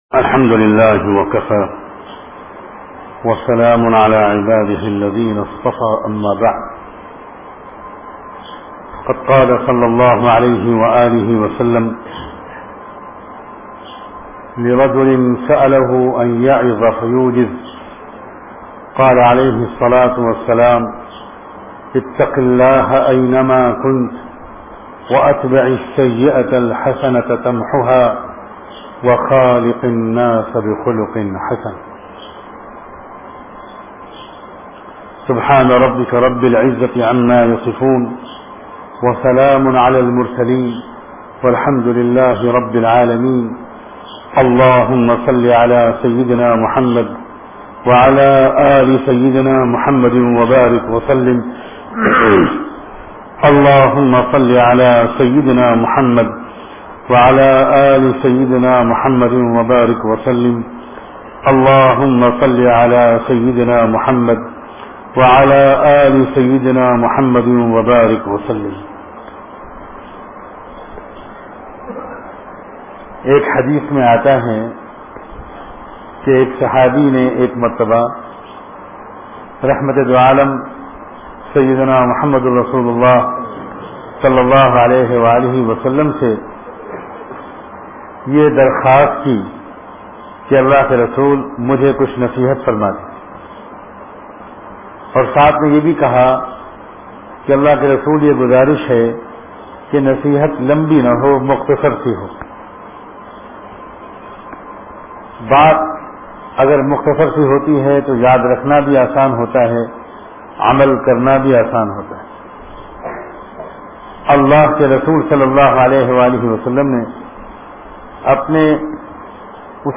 Allah Ka Wali Kaise Banen bayan mp3